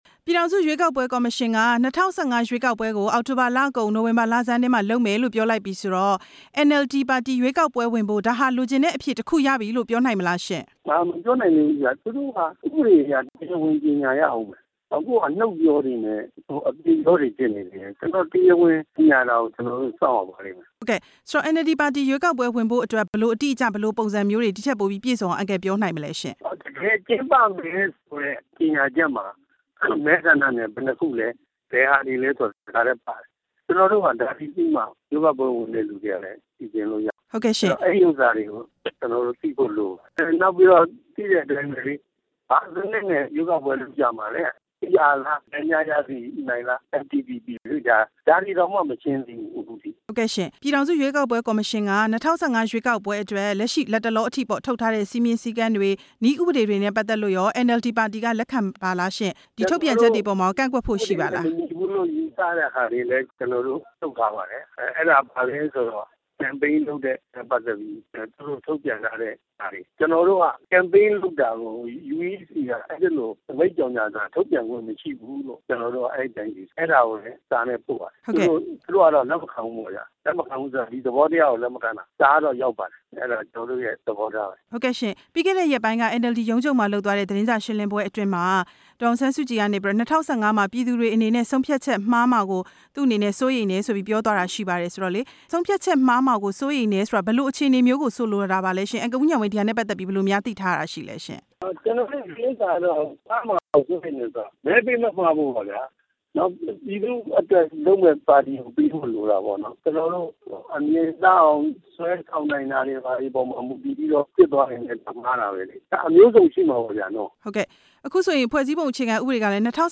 NLD ပါတီရွေးကောက်ပွဲ ဝင်၊ မဝင် မေးမြန်းချက်